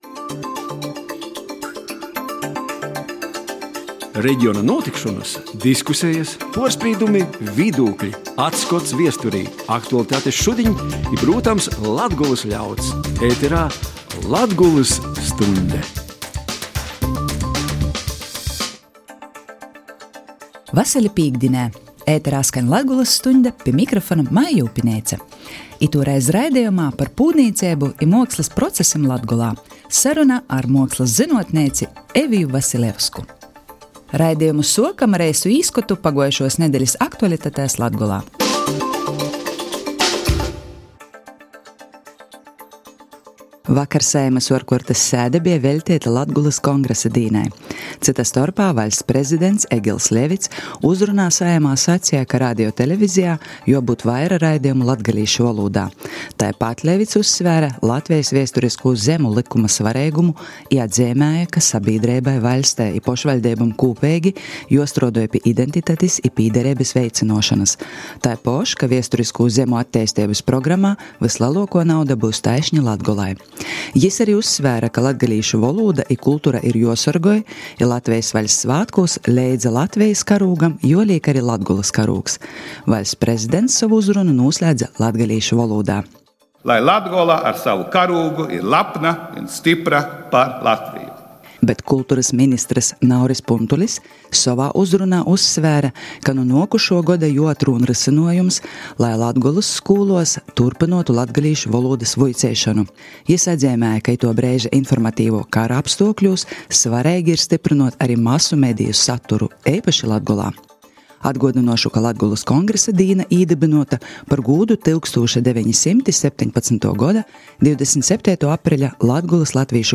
sarunā